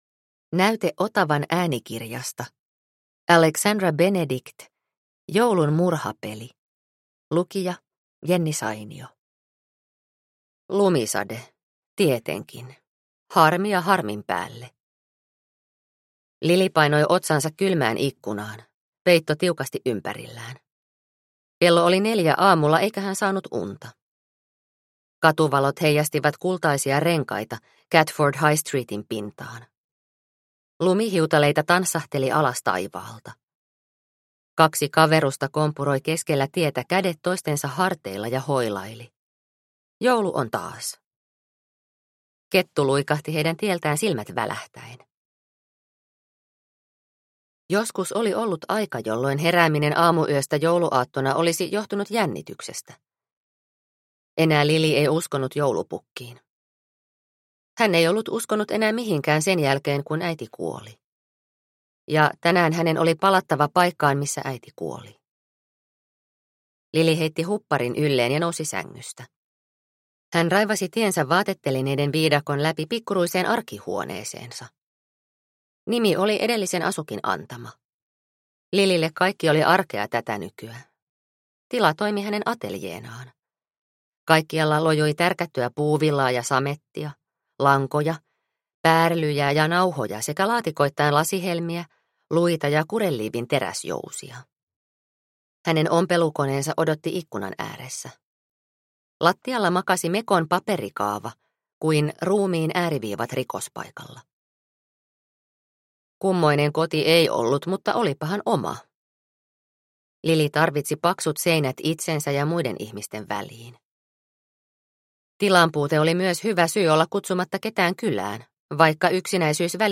Joulun murhapeli – Ljudbok – Laddas ner